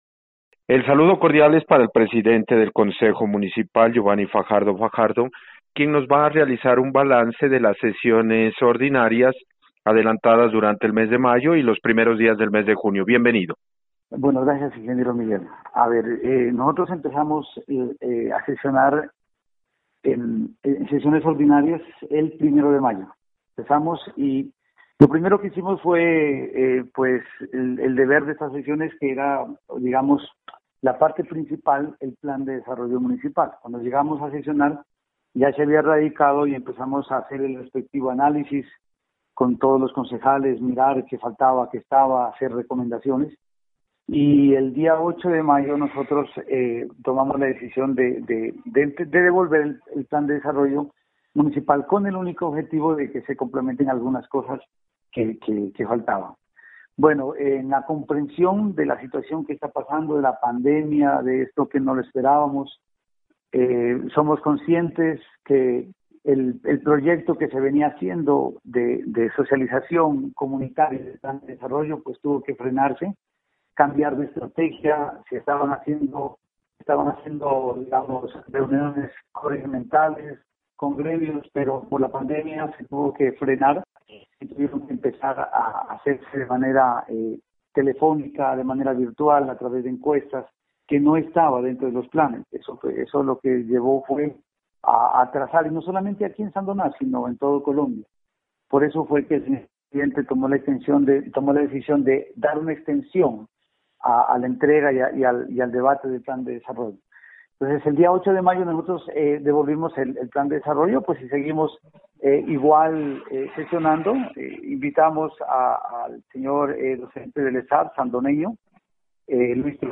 Balance de las sesiones ordinarias con el presidente del Concejo Geovanny Fajardo Fajardo: